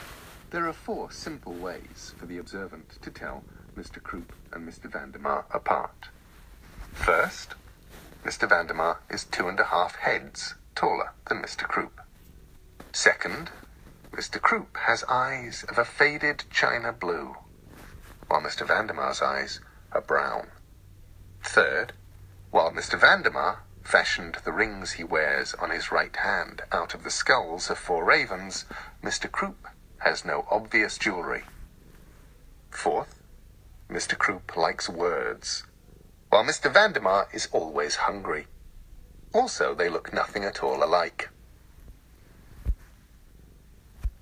audiobooks
Normally authors aren’t great at narrating their own works but Gaiman is the exception.
And here is a clip of him reading Neverwhere (one of my favorite of his books).